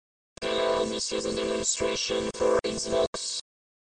Xvox is a  real time musical vocoder. Connect a microphone and a synthesiser to your computer soundcard and Xvox synthesises voice with the sound of your synthesiser.
This a demonstration...with stereo effect (mp3)
Xvox   is not , but sounds quite like them.
demo-en-stereo.mp3